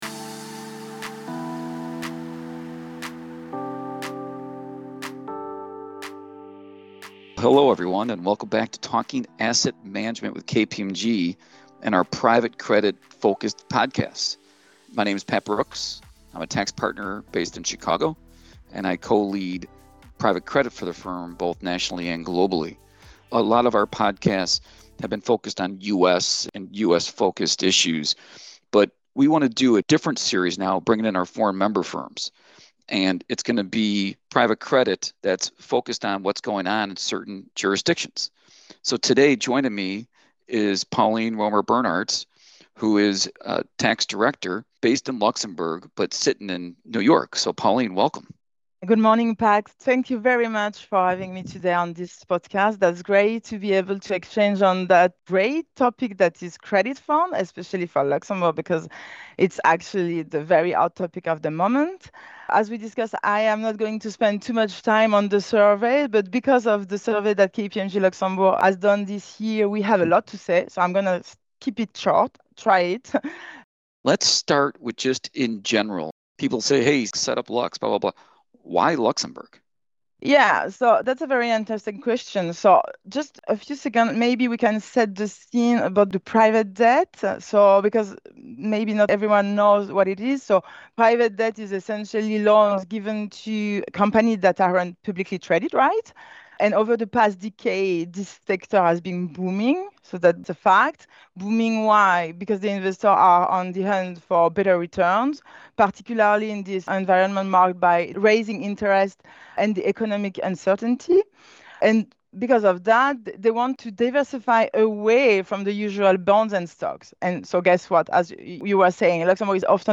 hosts a conversation with a leader from KPMG Luxembourg to discuss the current state of the private credit landscape in the region.